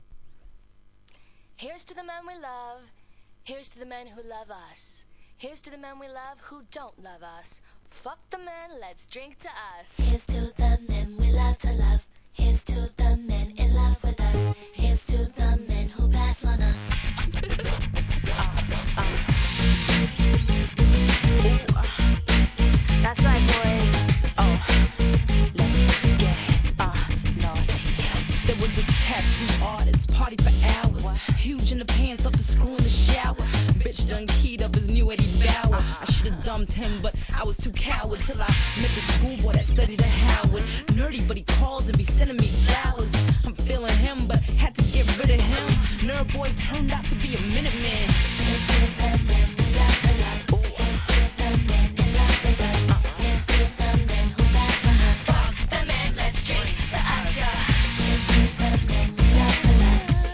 HIP HOP/R&B
2003年、後半はDANCEHALLへ展開するテンション↑↑ナンバー!!!